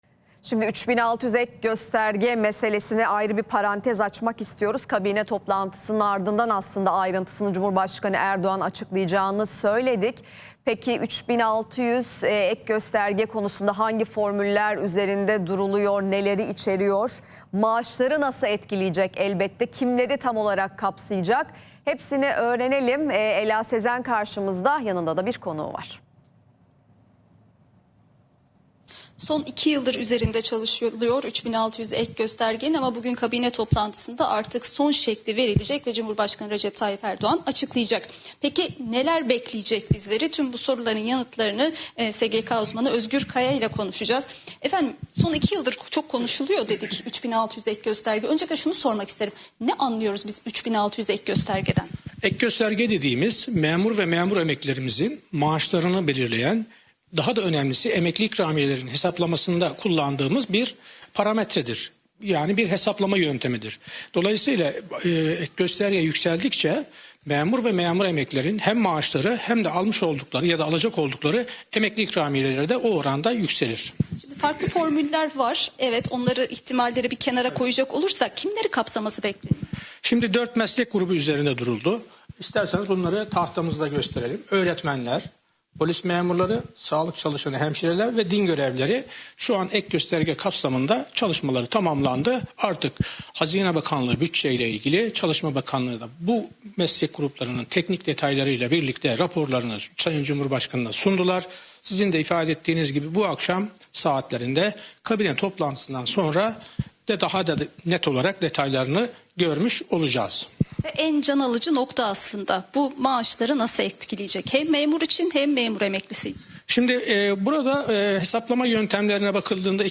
3600-ek-gosterge-sonrasi-hangi-memurun-maasi-ne-kadar-olacak-canli-yayinda-boyle-acikladi.mp3